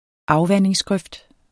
Udtale [ ˈɑwˌvanˀeŋs- ]